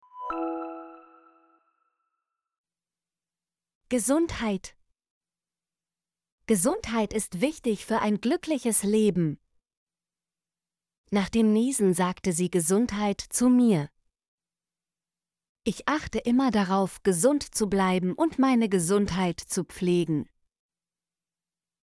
gesundheit - Example Sentences & Pronunciation, German Frequency List